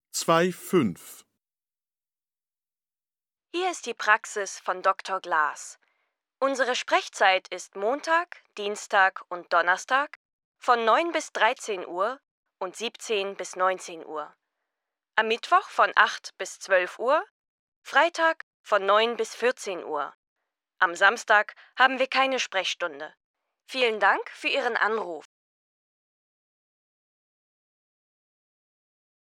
Hallgassátok meg a párbeszédet és töltsétek ki a határidőnaplót.